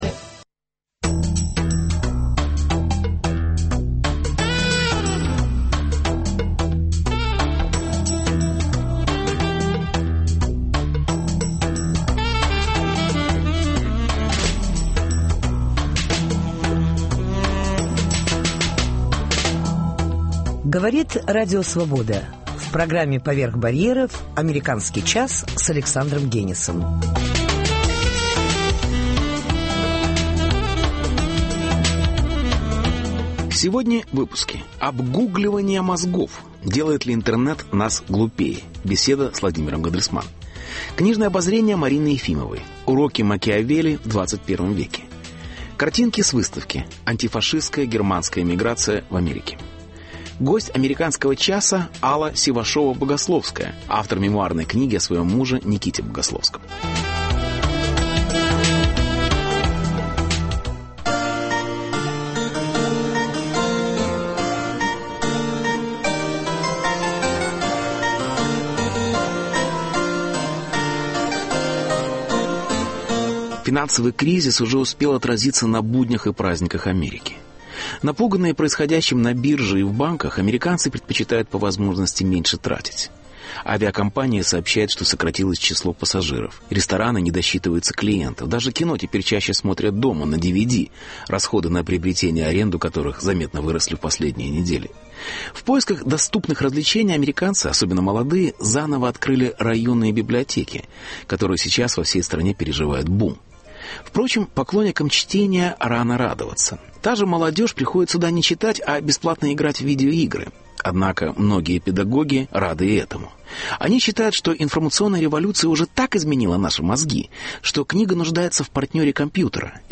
Дискуссия.
Интервью.